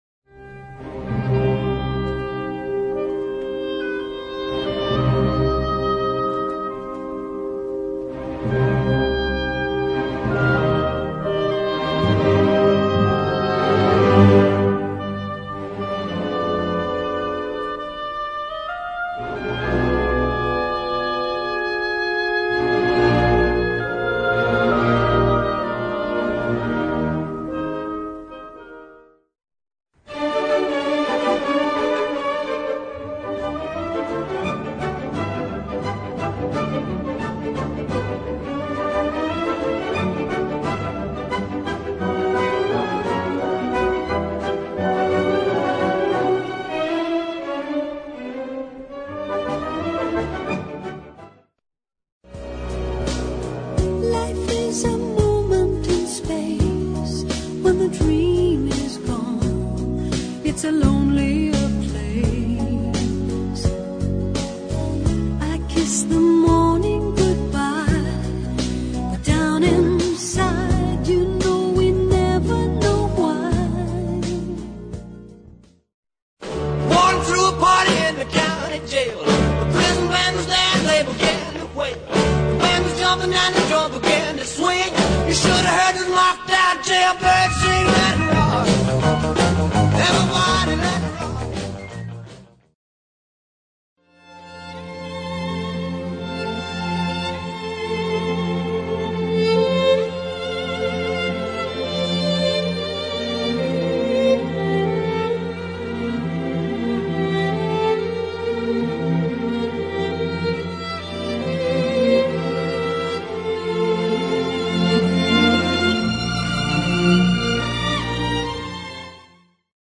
Musica tradizionale celtica